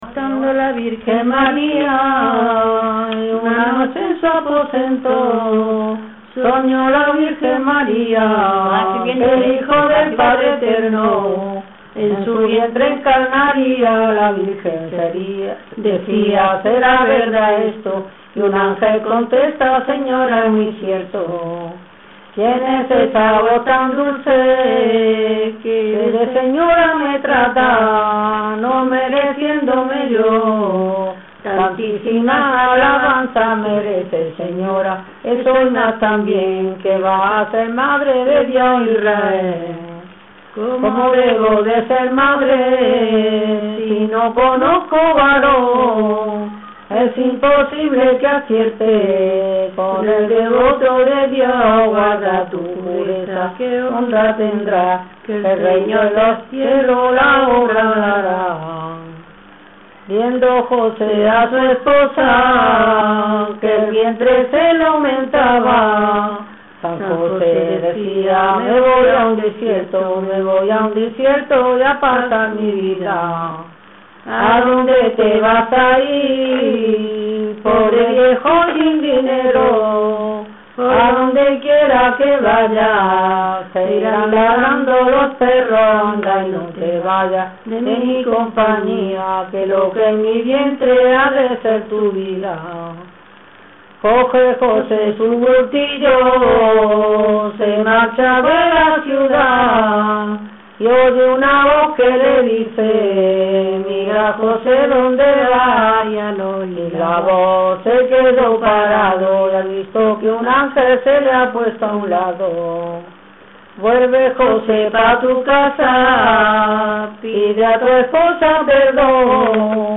Materia / geográfico / evento: Villancicos Icono con lupa
Játar (Granada) Icono con lupa
Secciones - Biblioteca de Voces - Cultura oral